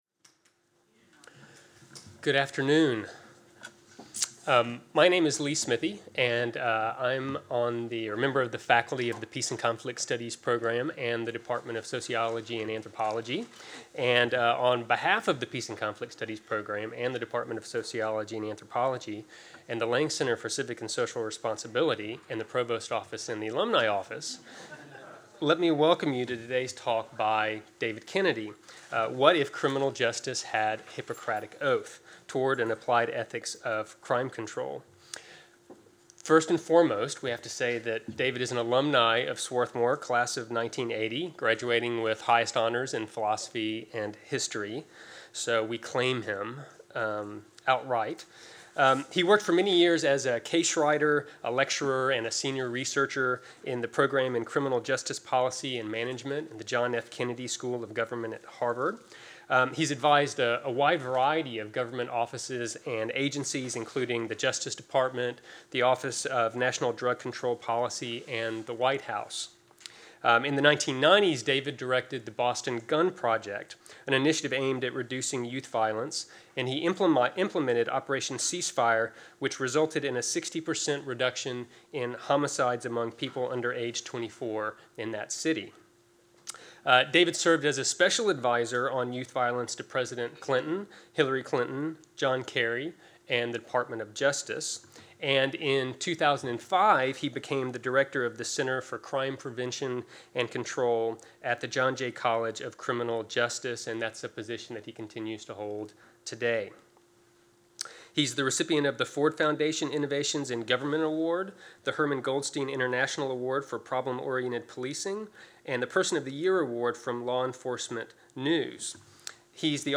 In this recent campus talk, criminiologist David Kennedy '80 explores an applied ethics of crime control.